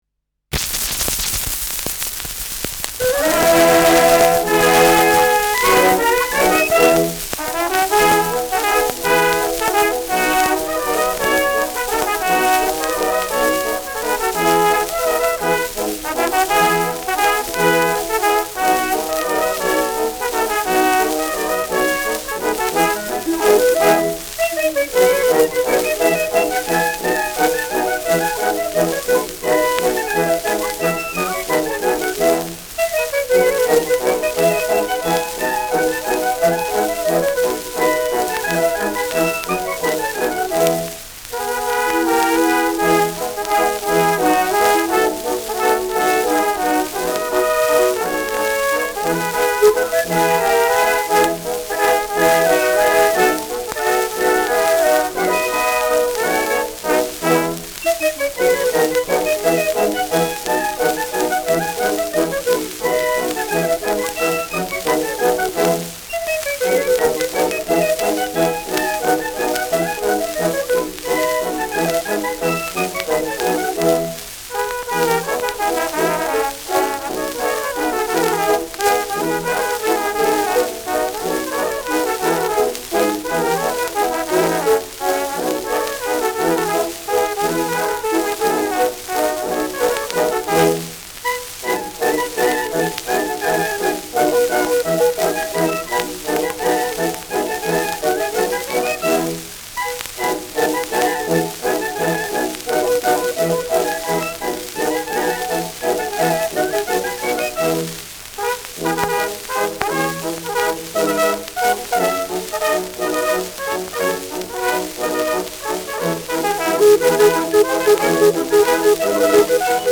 Schellackplatte
präsentes Rauschen : präsentes Knistern : abgespielt : „Schnarren“ : leiert : Nadelgeräusch : vereinzeltes Knacken
Mit Juchzer.